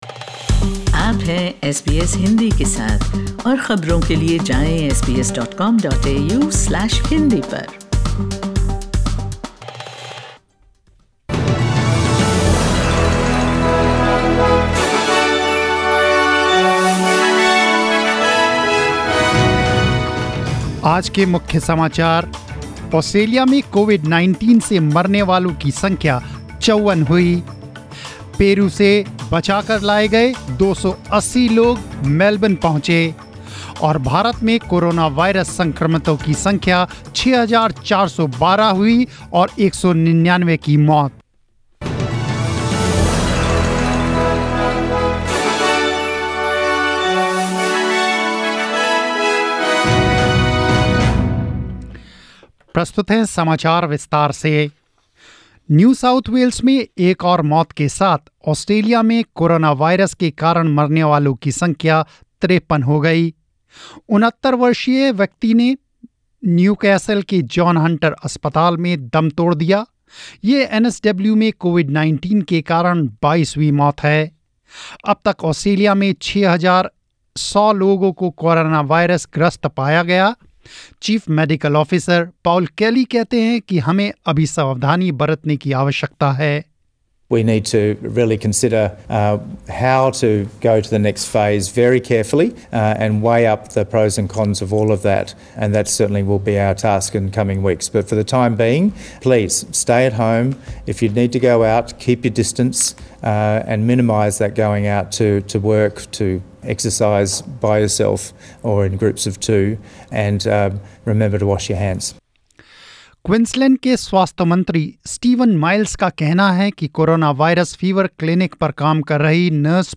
News in Hindi 10 April 2020